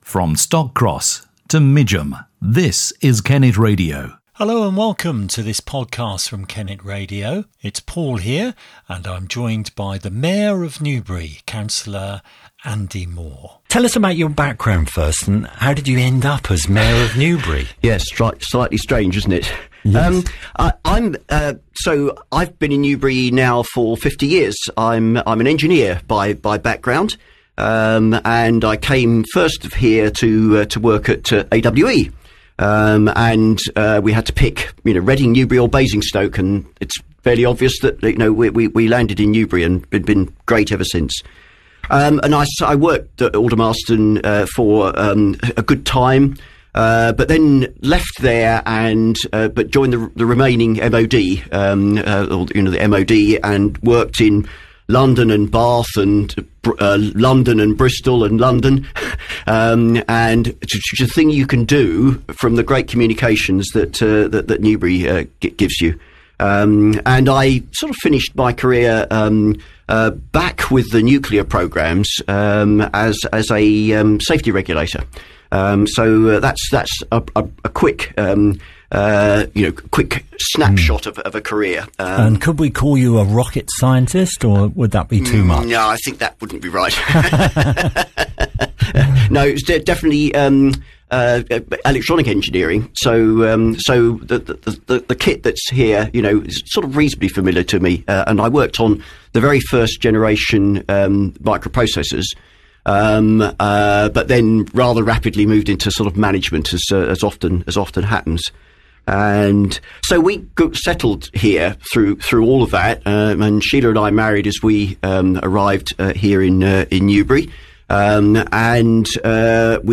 Last Saturday, Newbury’s Mayor, Councillor Andy Moore, visited Kennet Radio’s studio.